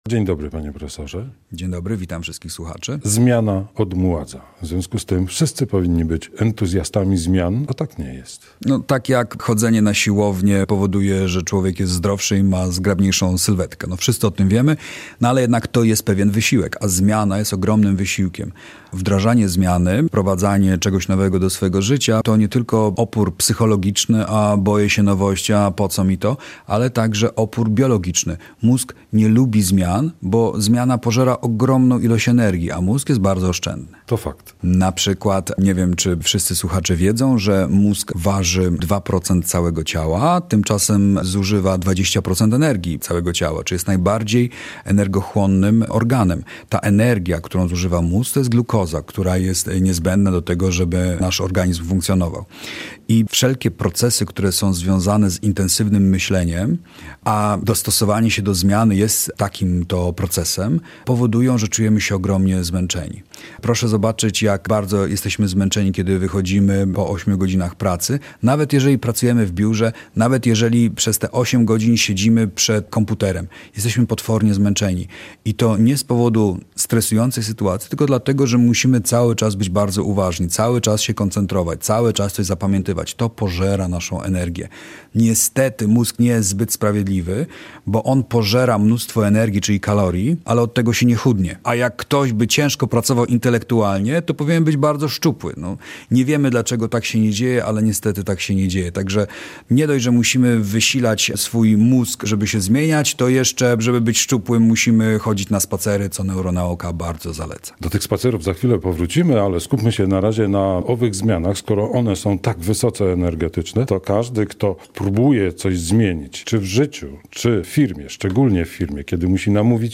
Rozmowa z neuropsychologiem